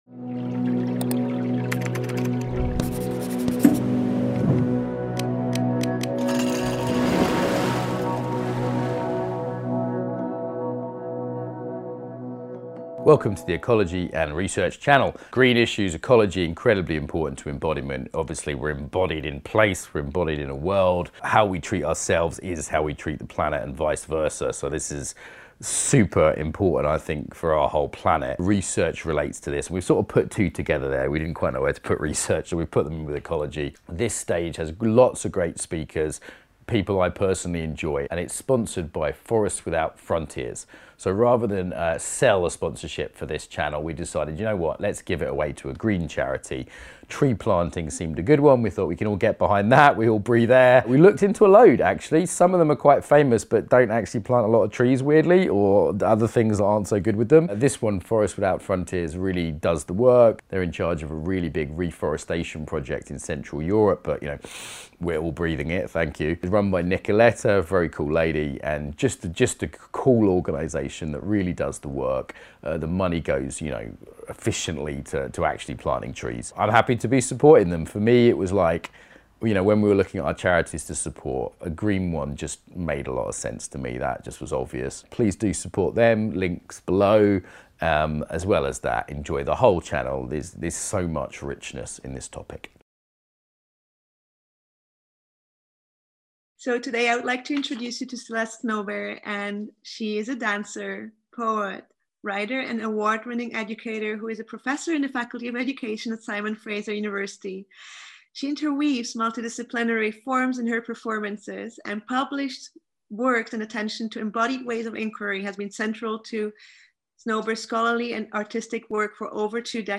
The Body as Portal: Embodied Ways of Inquiry Beginning or no understanding of topic, Open to all Some standing/ movement Likely soothing This performative presentation will explore the body as a place of deep listening and a portal to the terrain of the inner life. We will explore embodied ways of inquiry which break open what it means to honour the terrain of the body and cultivate creativity from the inside out.